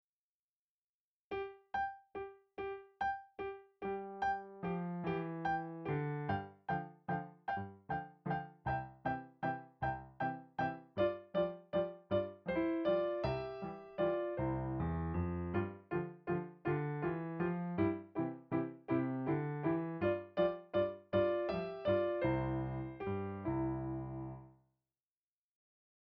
Voicing: 1 Piano 4 Hands